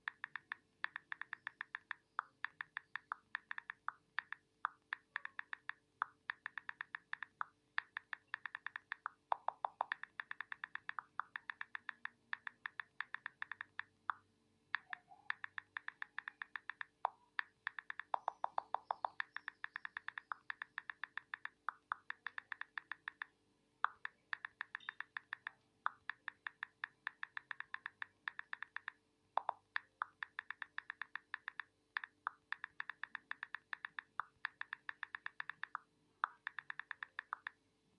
Звук набора сообщения в Telegram клавиатура